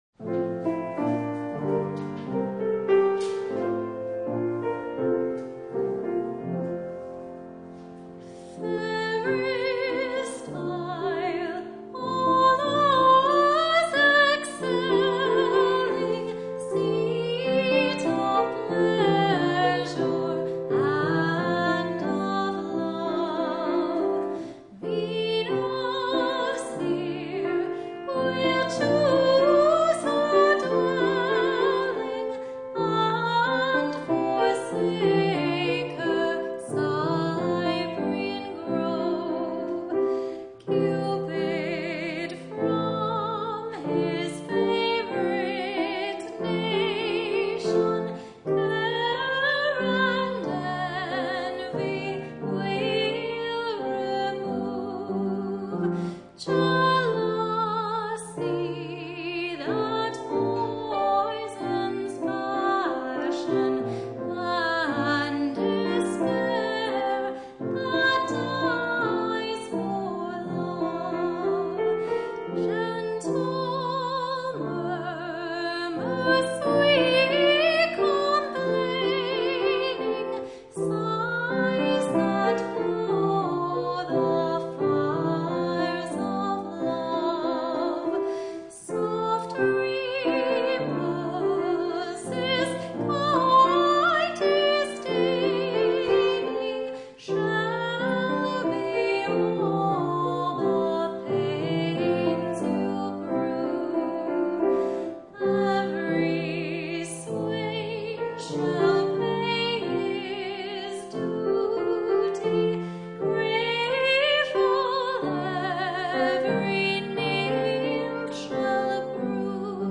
She is a performer, and has a magnificent opera voice.